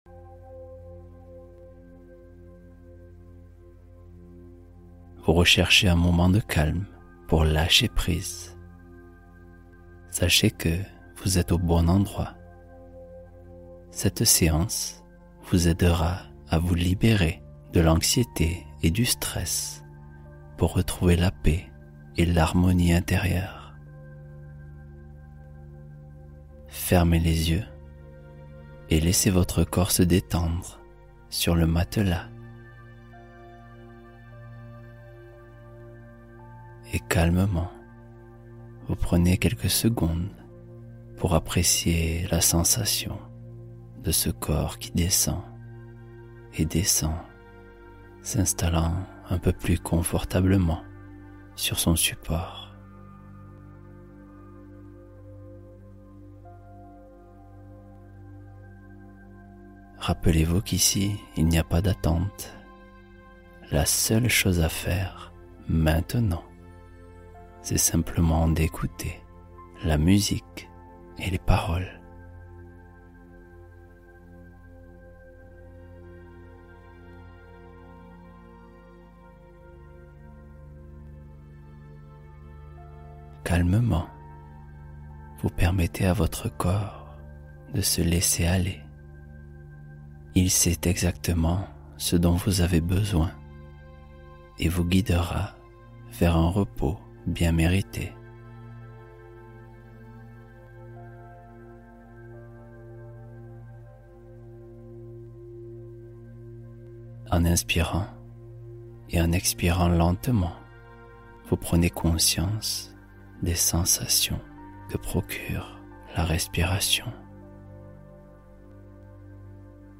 Secret du Sommeil : Méditation douce pour s'endormir en quelques minutes